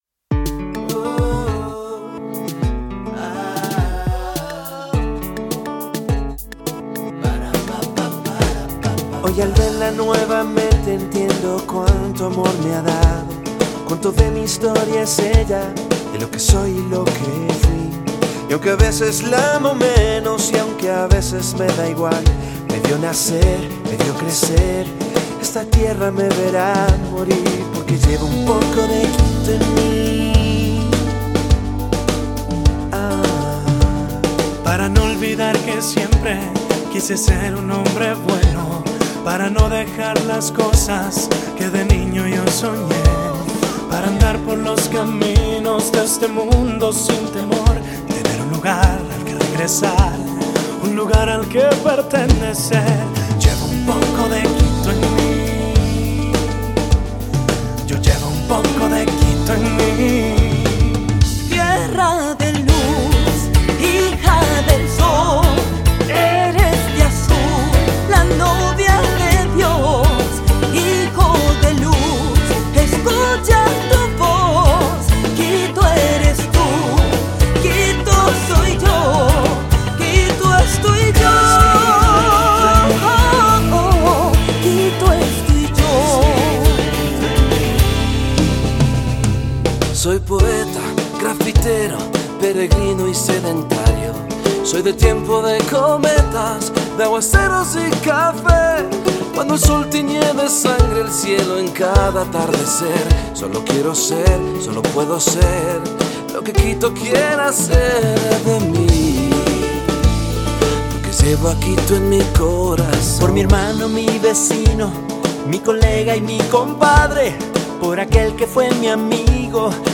Bateria
Guitarras
Bajo
Instrumentos Andinos
Coros
Teclados y Mandolín
Coros grabados en Jeanius Lab Studios - Hialeah, FL.